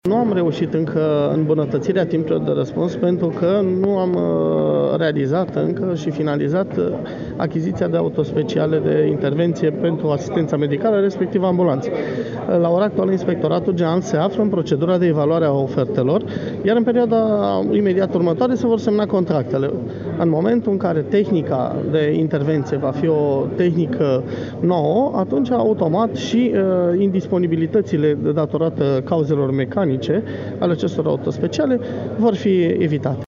Şeful Inspectoratului General pentru Situaţii de Urgenţă, Daniel Dragne a fost prezent la Arad la prezentarea bilanţului ISU Arad pe 2017, el apreciind  activitatea salvatorilor arădeni ca fiind foarte bună.